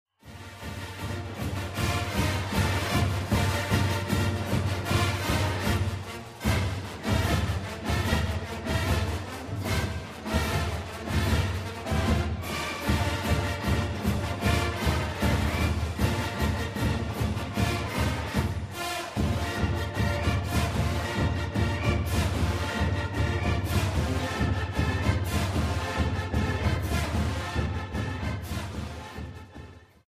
Marching Bands